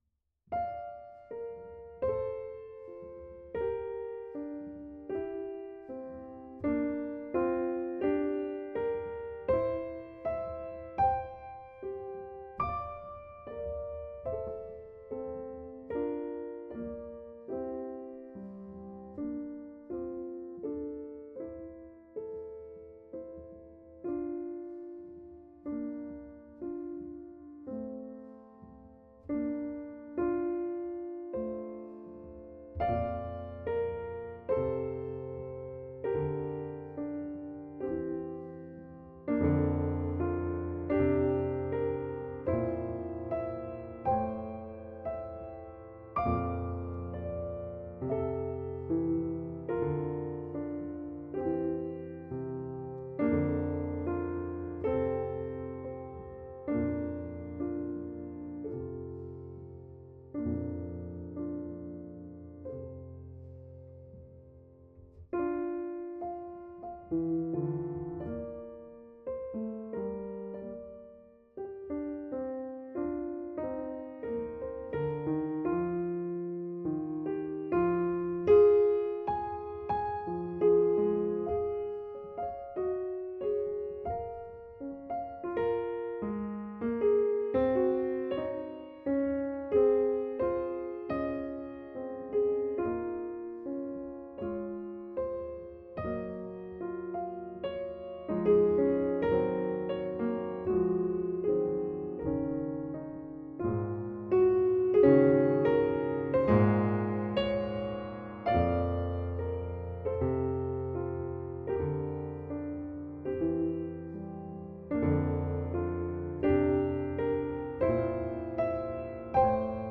piano
Recorded in a tractor barn in Colorado’s Vail Valley in 2006